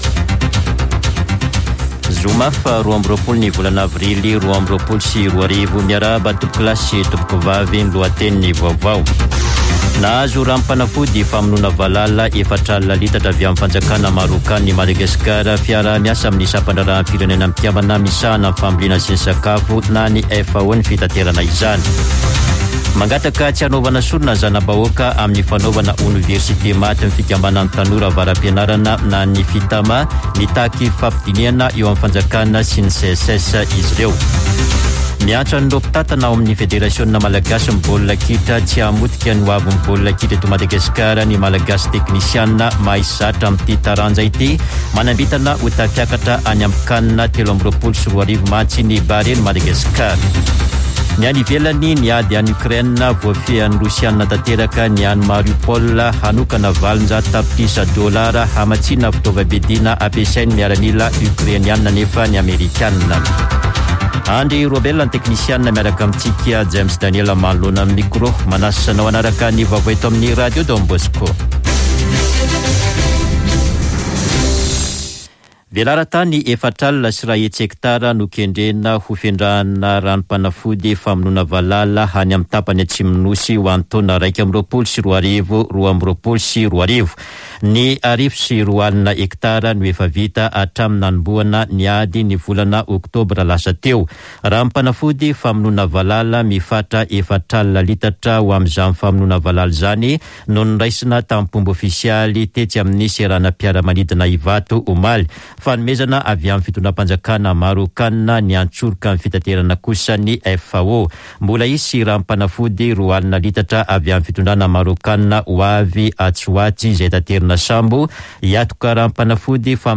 [Vaovao maraina] Zoma 22 aprily 2022